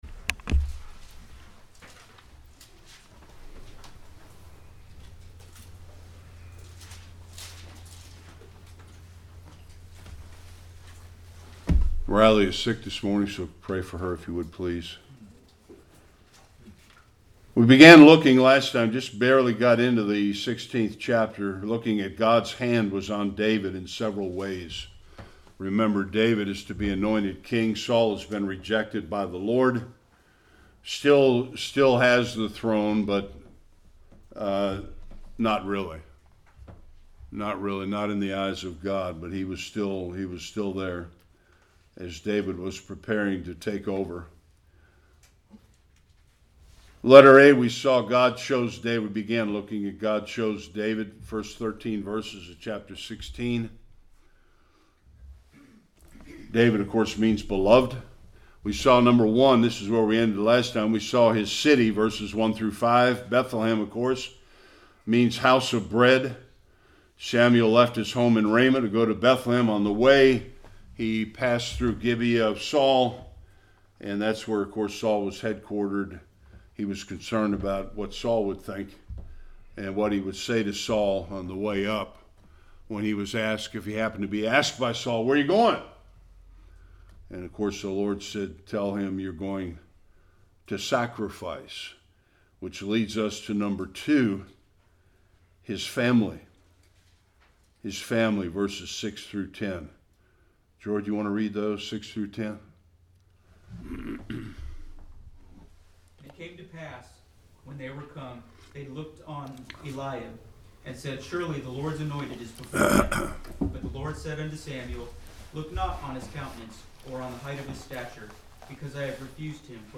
1-31 Service Type: Sunday School Samuel anoints David as king as Saul continues his fall.